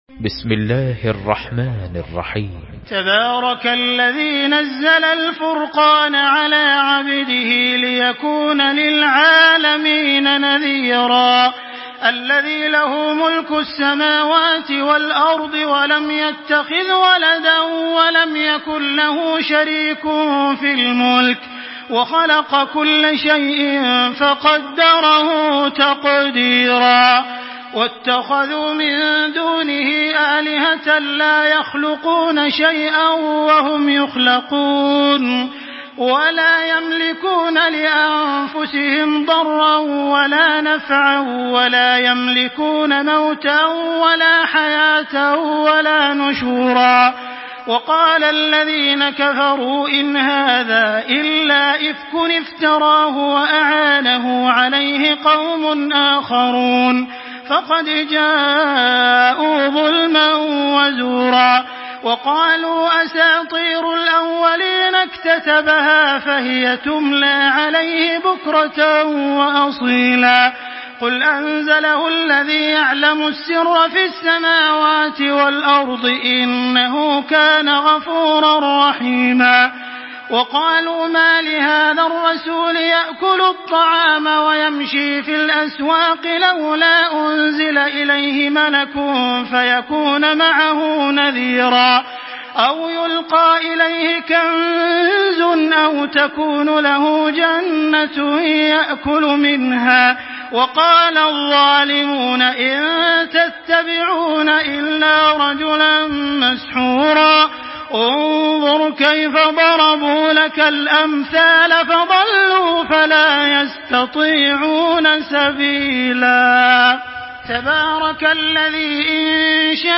تحميل سورة الفرقان بصوت تراويح الحرم المكي 1425
مرتل